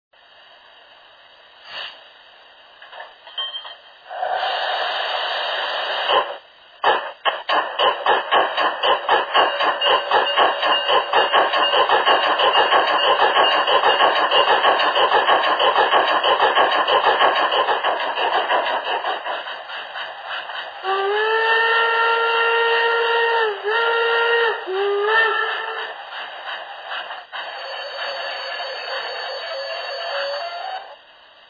Locomotora vapor
DL-246486 Loc vapor BR 24/64/86 EU